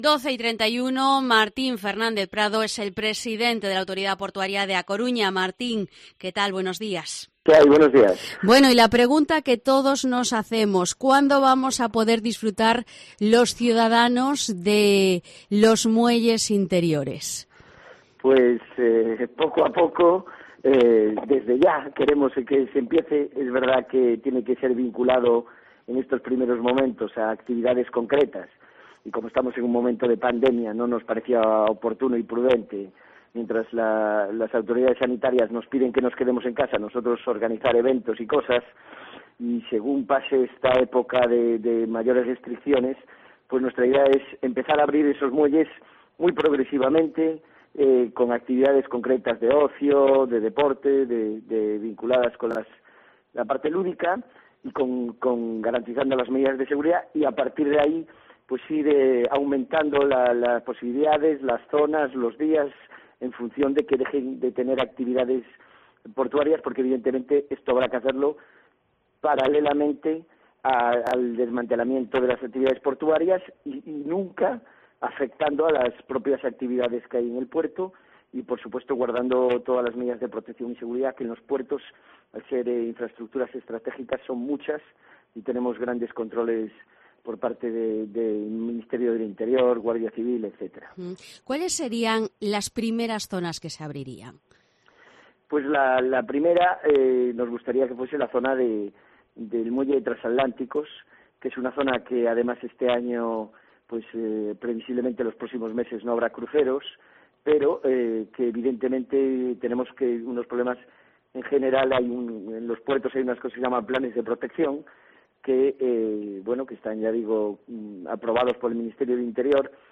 Entrevista a Martín Fernández Prado, presidente de la Autoridad Portuaria de A Coruña